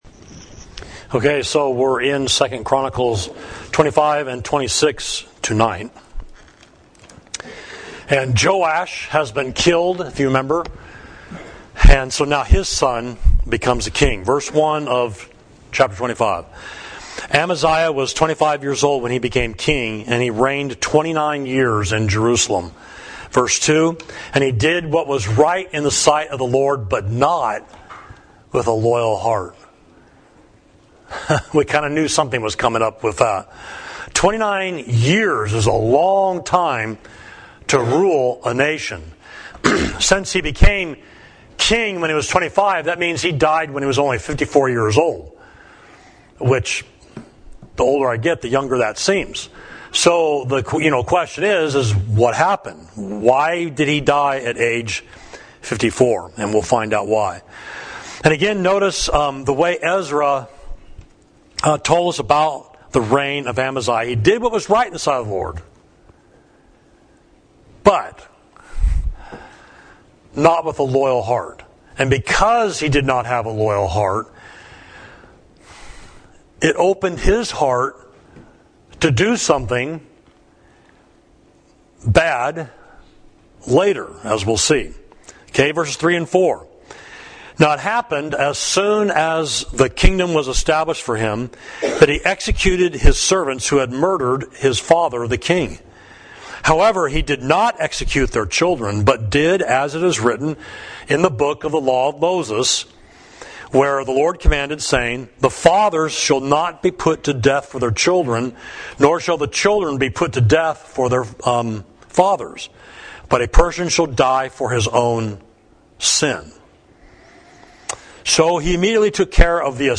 Sermon: The Lord Is Able to Give You Much More